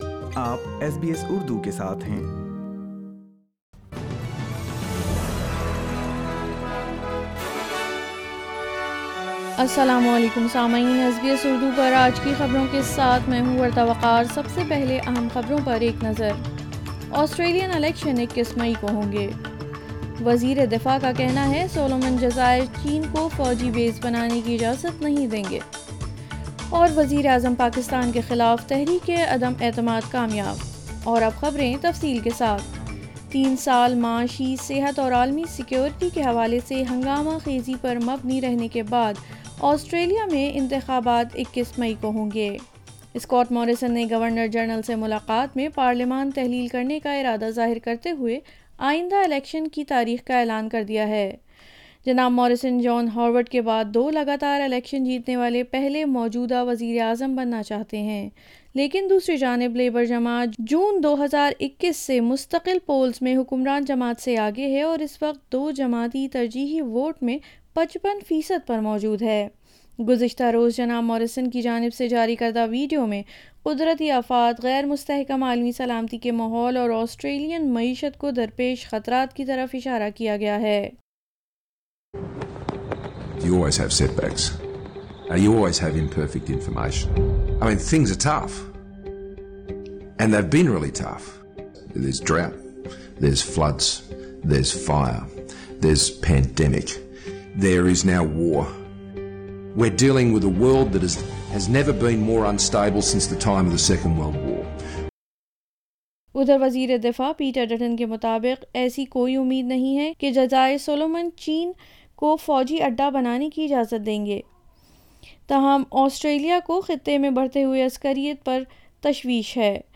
Australia will go to the polls on the 21st of May. NATO is working on plans for a permanent military presence on its eastern border For more details listen Urdu news